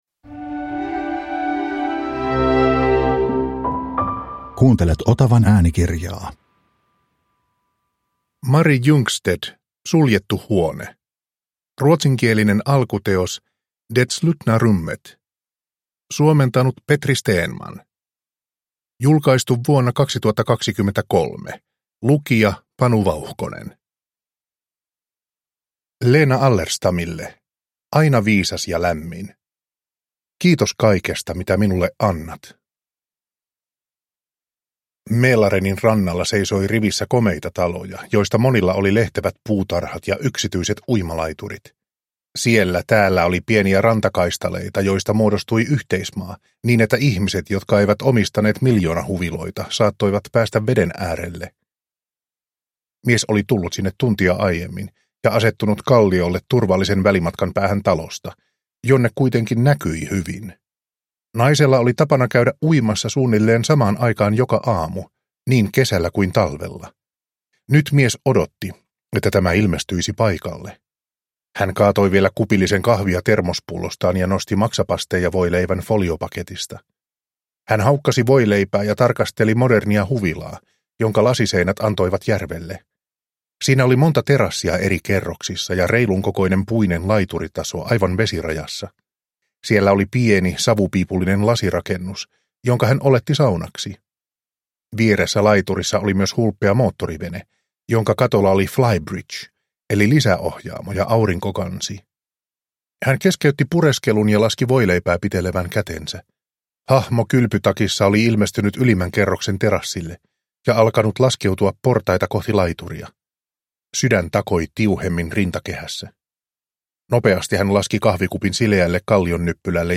Suljettu huone – Ljudbok – Laddas ner